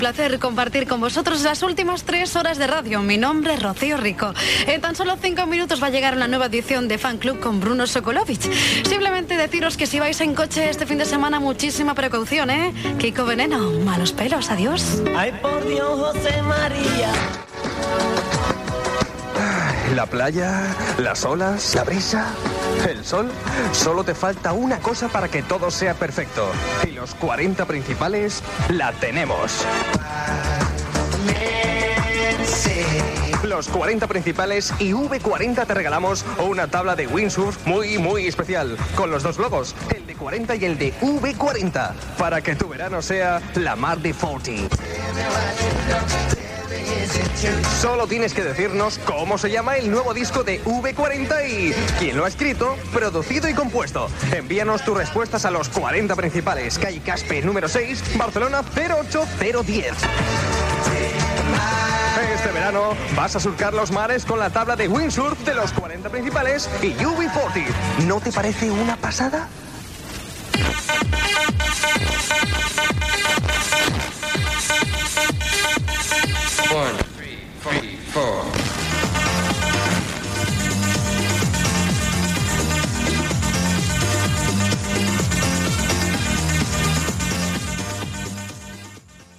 Comiat d'un torn de continuïtat i falca concurs.
Musical
FM
Gravació realitzada a València.